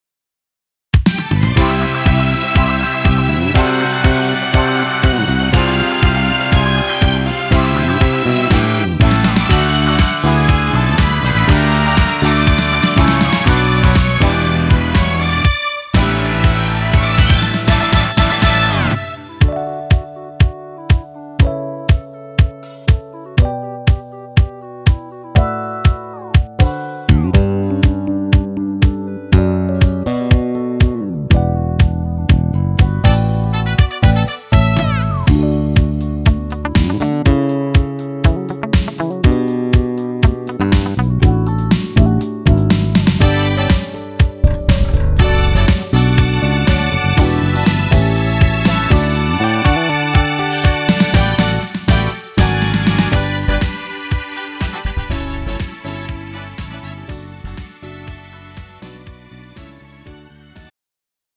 음정 원키 3:51
장르 가요 구분 Pro MR